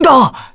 Roi-Vox_Damage_kr_02.wav